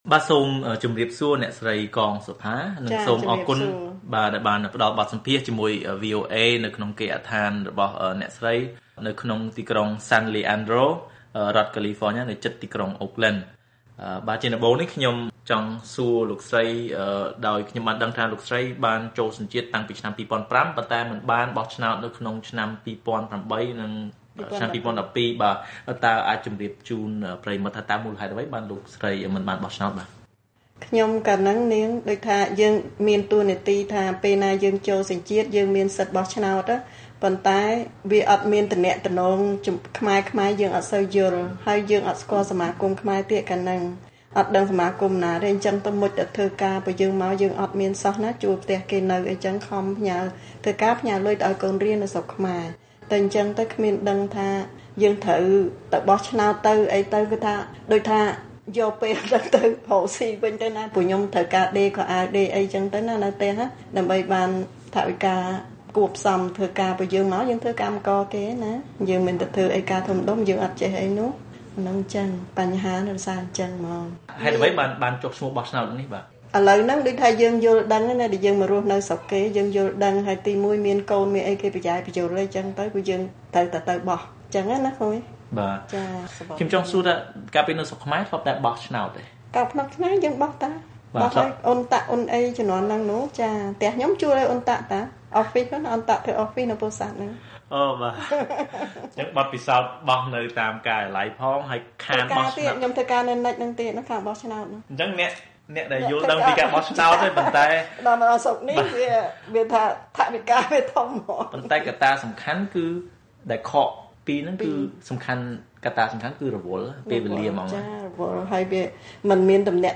បទសម្ភាសន៍ VOA៖ អតីតបុគ្គលិកគ.ជ.ប.ចែករំលែកពីបទពិសោធន៍បោះឆ្នោតនៅអាមេរិក